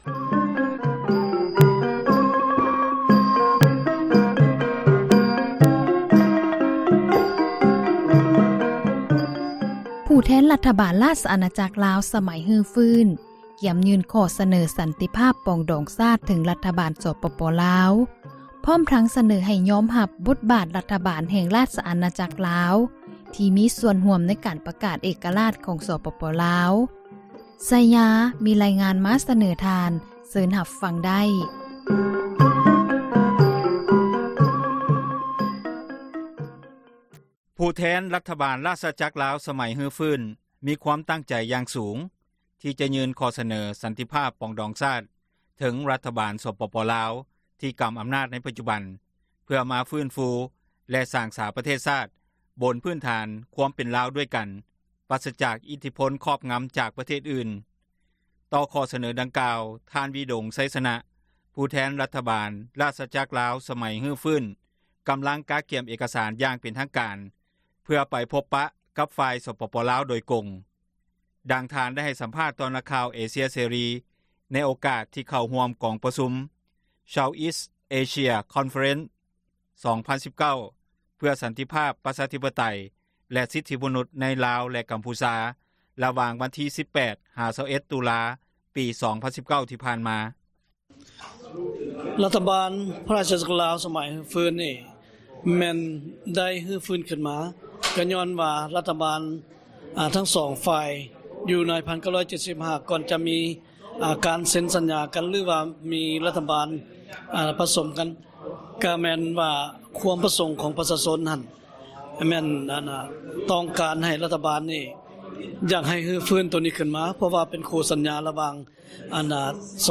ດັ່ງທ່ານໄດ້ໃຫ້ສຳພາດ ຕໍ່ນັກຂ່າວເອເຊັຽເສຣີ ໃນໂອກາດທີ່ເຂົ້າຮ່ວມ ກອງປະຊຸມ Southeast Asia Conference 2019 ເພື່ອສັນຕິພາບ ປະຊາທິປະໄຕ ແລະ ສິດທິມະນຸດ ໃນລາວ ແລະ ກຳພູຊາ ຣະຫວ່າງ ວັນທີ 18-21 ຕຸລາ 2019 ຜ່ານມາ.